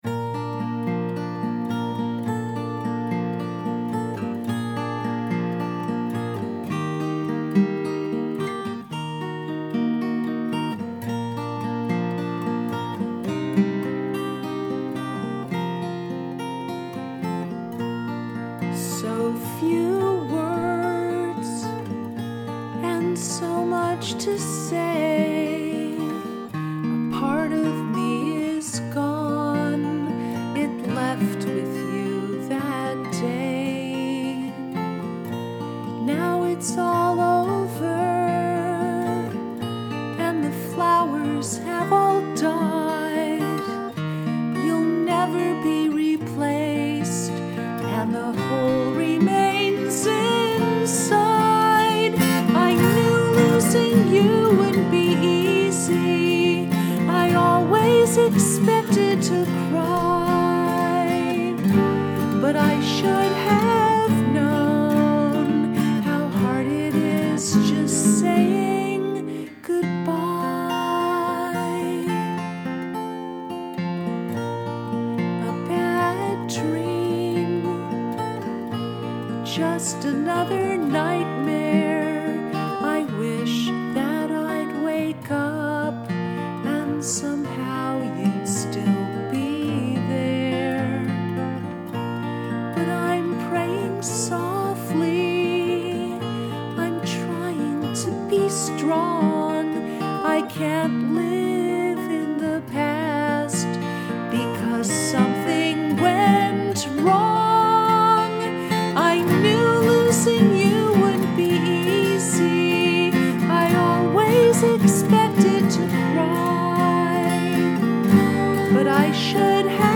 Saying Goodbye Piano Guitar 2019
saying-goodbye-acoustic-3-8-17-mix-3.mp3